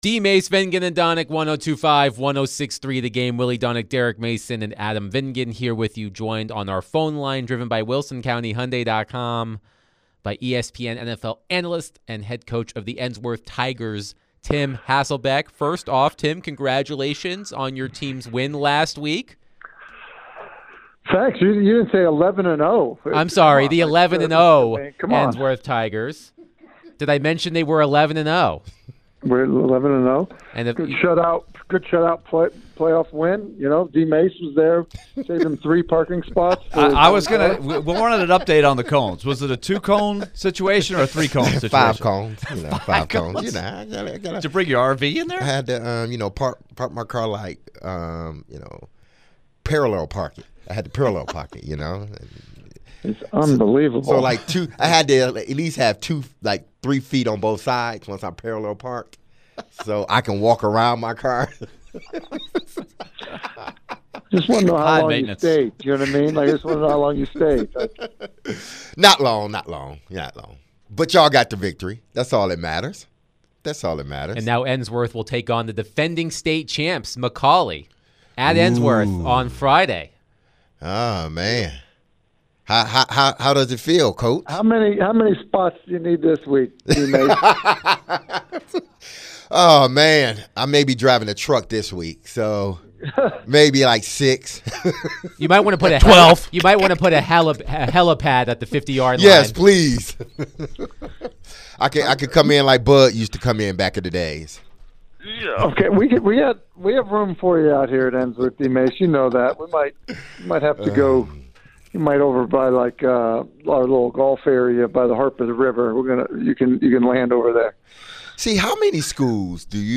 ESPN NFL Analyst Tim Hasselbeck joined the show to discuss the Titans' loss to the Vikings on Sunday. What did he think of some of the controversial penalties called against the Titans?